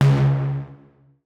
UIClick_Ugly Distortion 02.wav